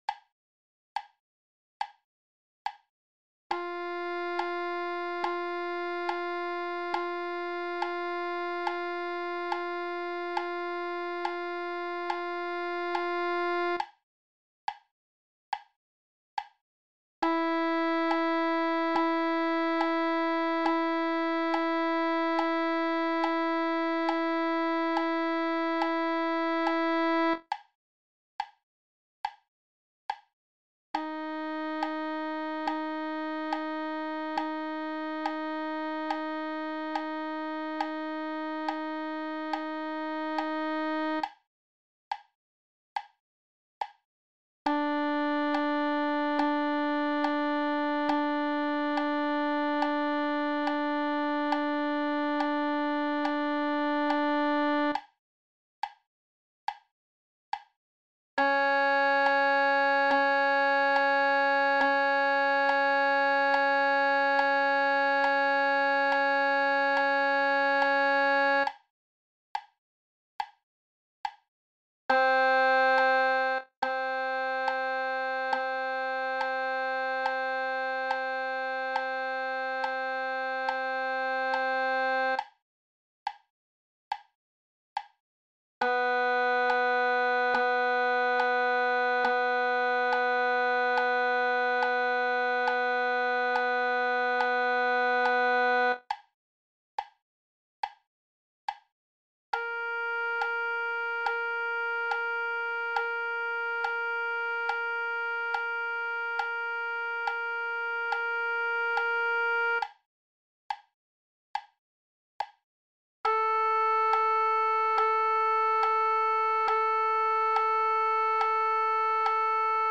Play-Along Tracks - simple tracks to help with intonation and tone development
for B-flat trumpet